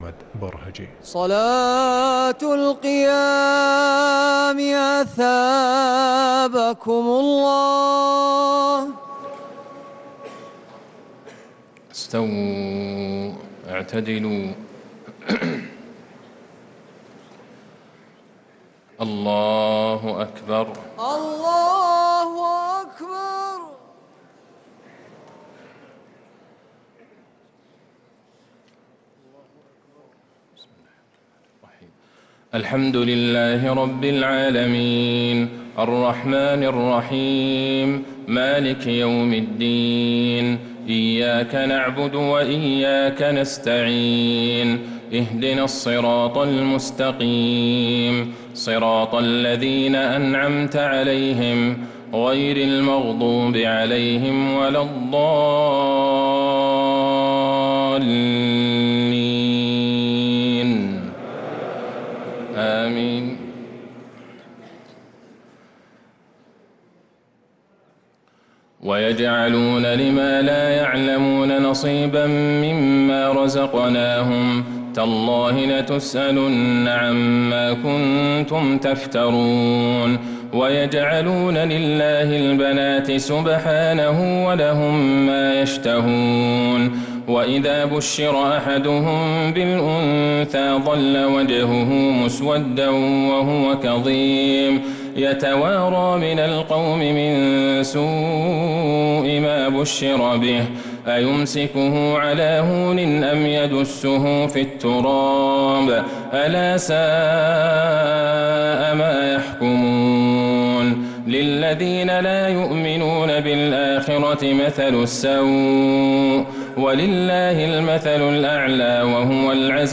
تراويح ليلة 19 رمضان 1446هـ من سورة النحل (56-128) | taraweeh 19th night Ramadan 1446H Surah An-Nahl > تراويح الحرم النبوي عام 1446 🕌 > التراويح - تلاوات الحرمين